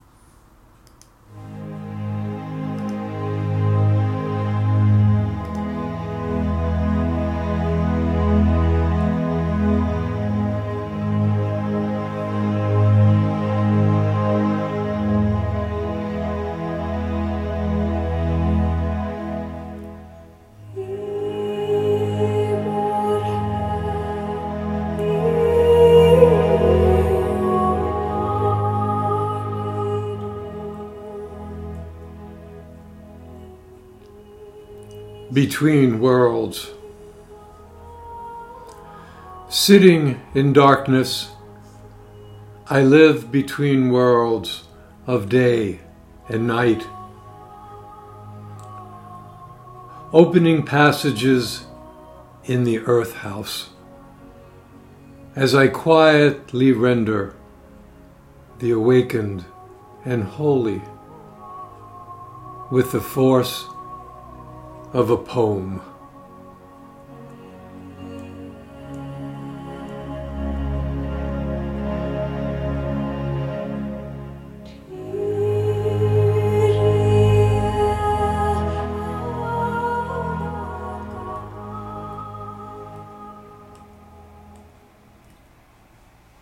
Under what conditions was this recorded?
Reading of “Between Worlds” with music by Enya.